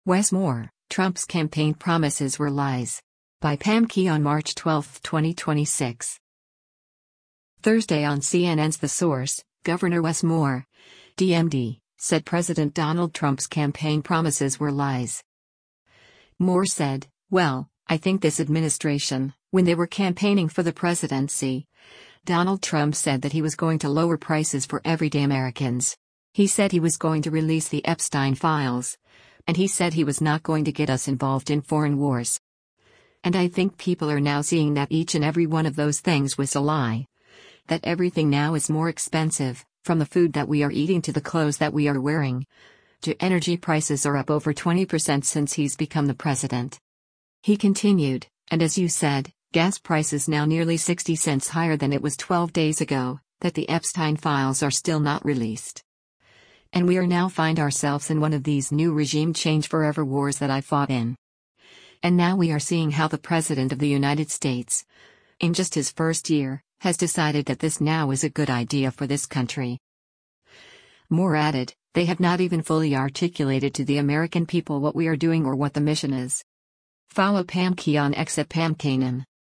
Thursday on CNN’s “The Source,” Gov. Wes Moore (D-MD) said President Donald Trump’s campaign promises were lies.